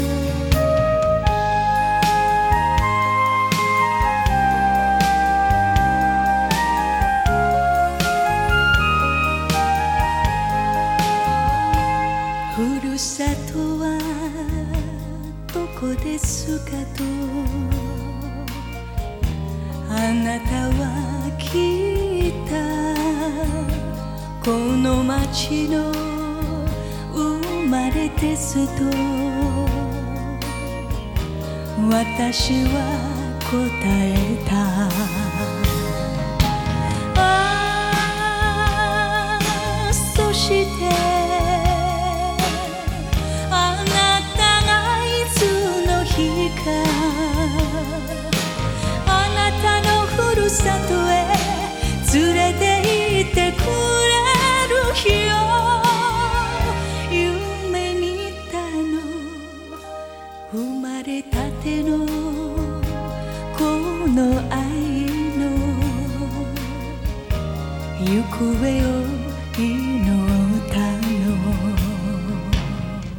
ジャンル: Japanese Pop